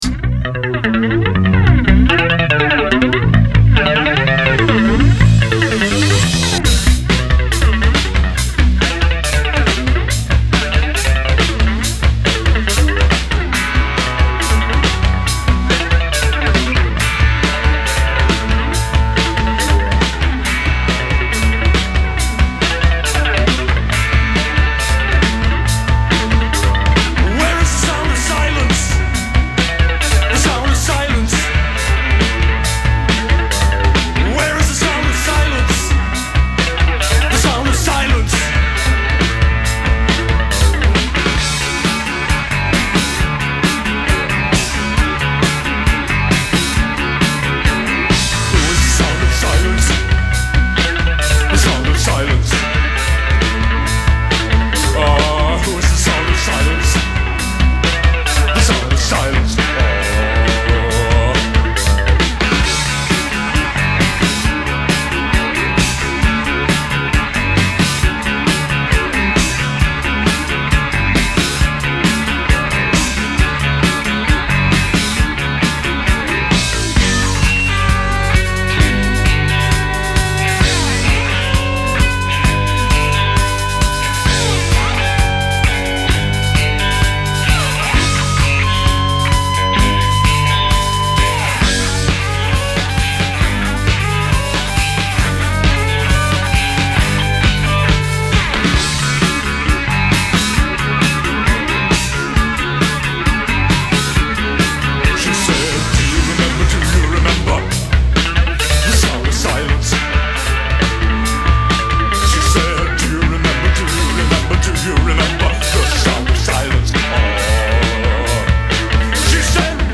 Вот тут сведение - будь здоров, зацените.